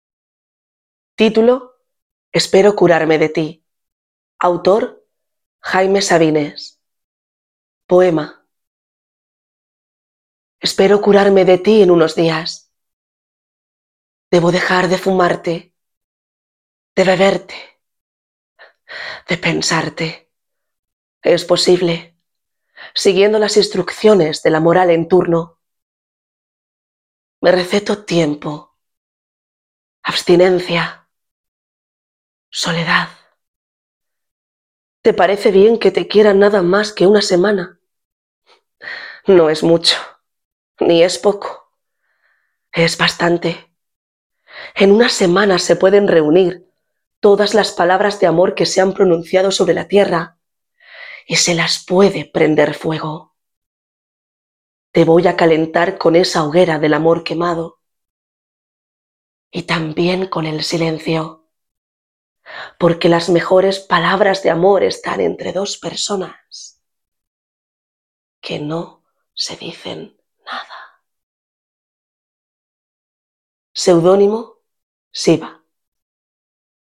Modalidad poesía
con la lectura “Espero curarme de ti” (Jaime Sabines).